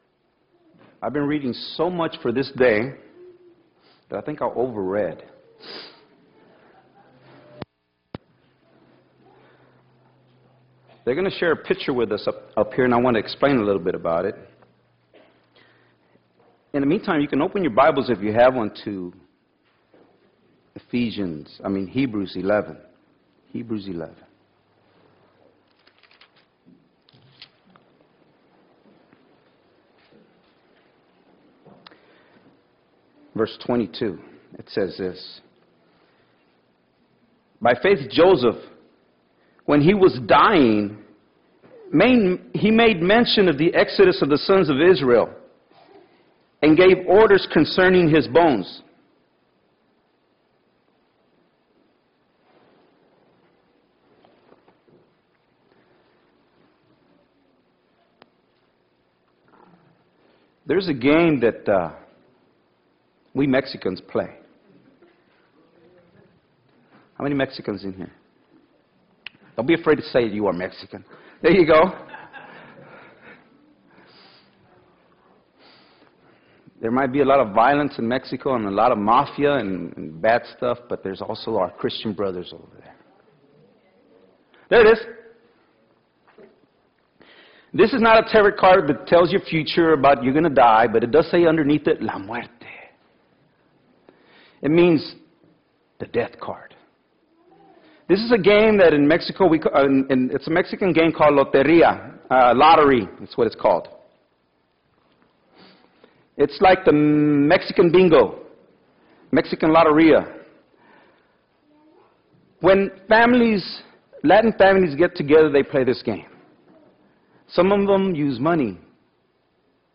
1-5-13 sermon